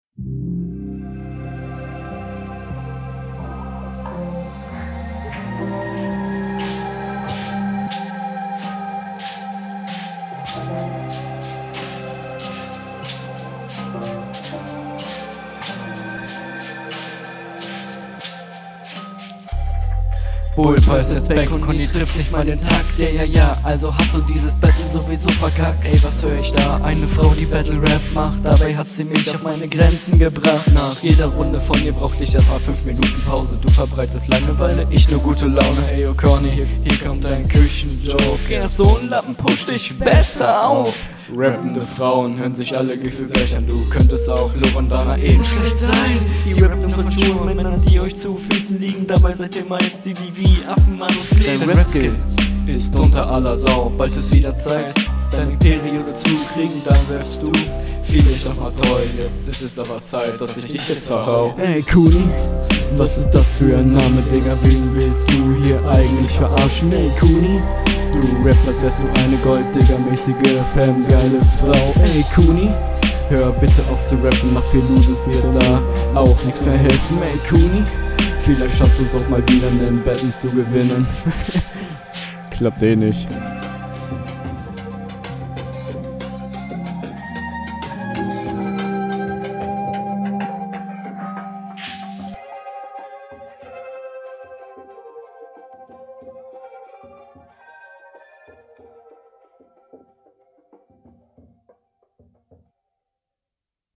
Flow: zu Anfang sehr holprig, wieder dann gegen Ende aber besser, könntest deine gesamte Spur …
Flow: Etwas besser als der Gegner, trotzdem noch paar Fehler.